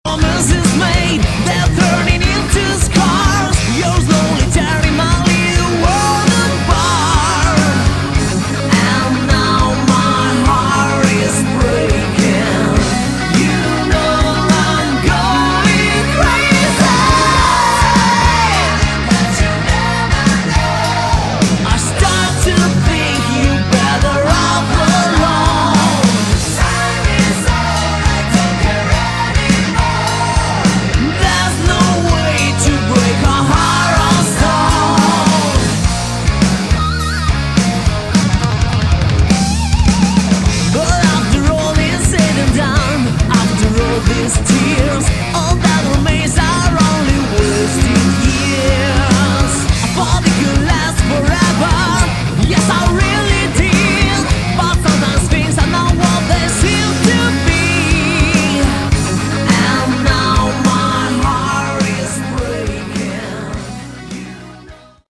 Category: Melodic Rock
vocals
guitars
keyboards
bass
drums